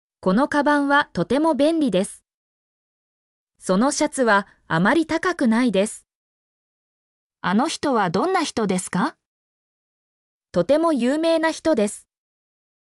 mp3-output-ttsfreedotcom-51_XABOnvEc.mp3